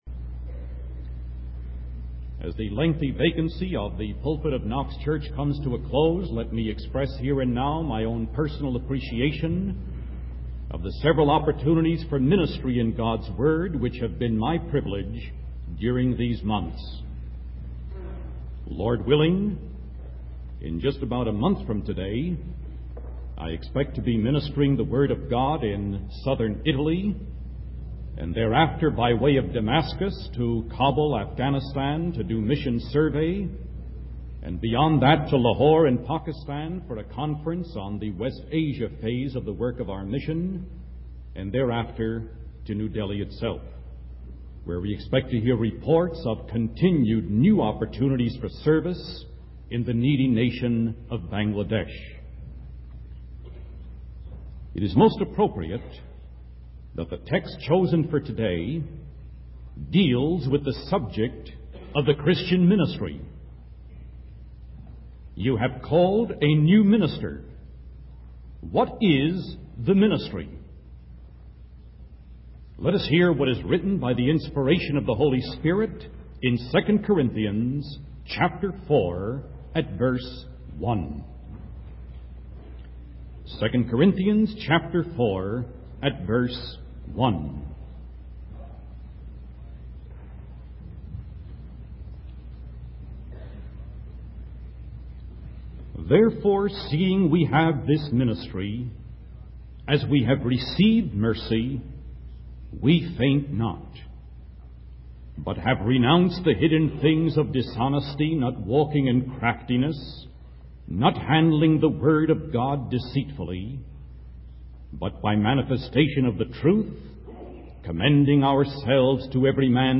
In this sermon, the preacher emphasizes the importance of the integrity of the gospel ministry. He highlights the need for a plain proclamation of the truth, without deception or distortion. The preacher also discusses the opposition faced by Christian ministry from Satan, who blinds the minds of unbelievers.